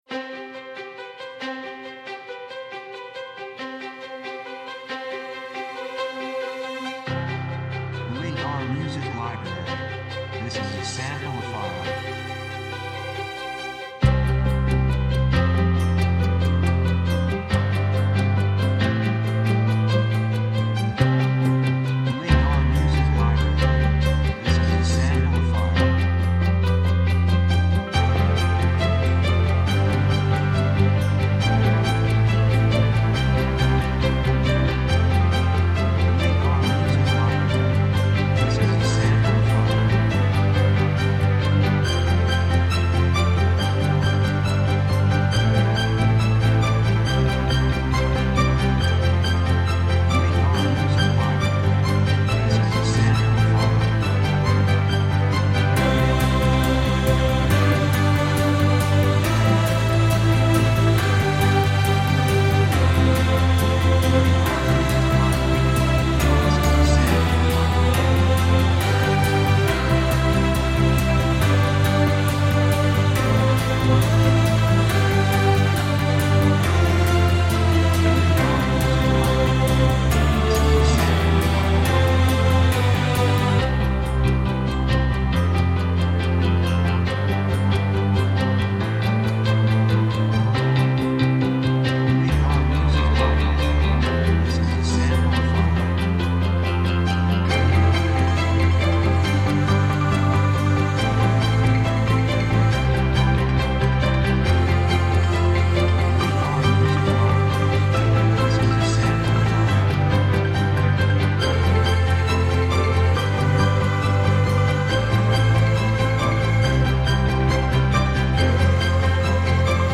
雰囲気幸せ, 高揚感, 決意, 喜び
曲調ポジティブ
楽器ピアノ, ストリングス, ボーカル, 手拍子
サブジャンルドラマ, オーケストラハイブリッド
テンポ速い